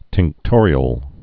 (tĭngk-tôrē-əl)